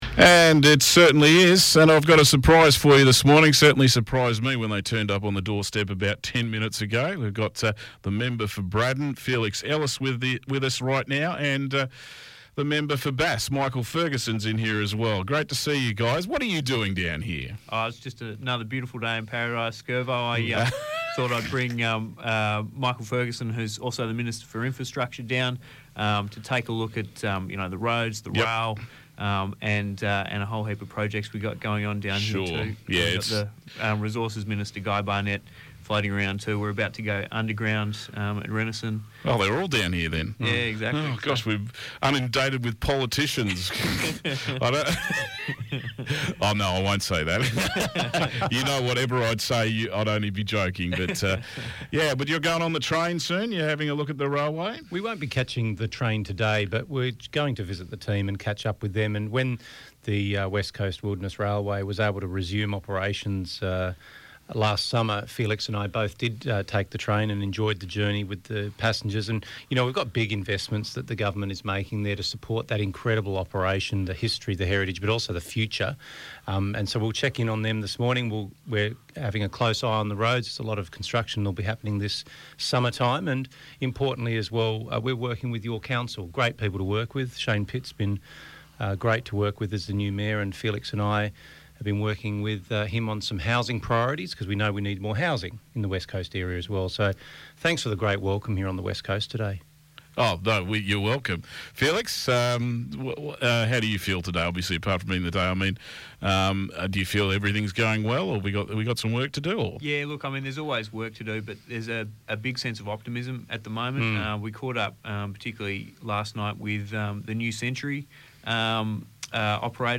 Liberal Minister and Member for Bass Michael Ferguson and the Member for Braddon Felix Ellis are on the West Coast today and you can hear that catch up right here